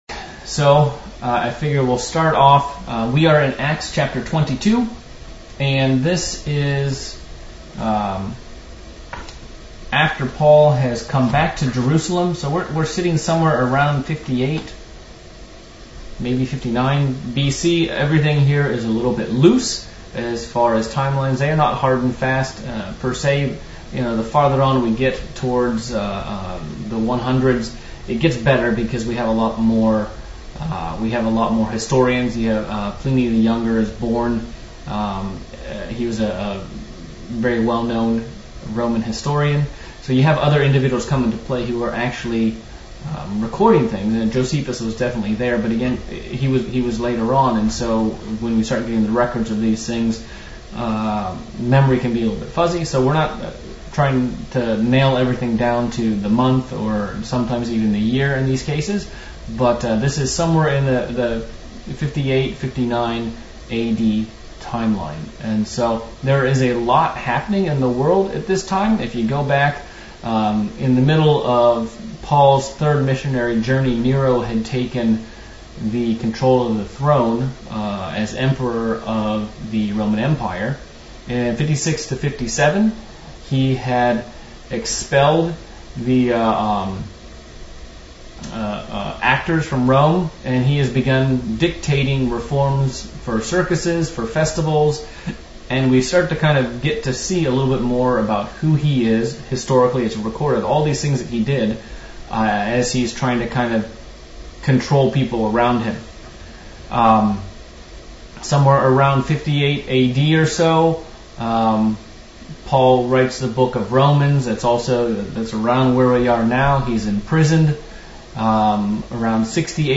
February Bible Study Acts 22